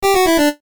jingles-retro_03.ogg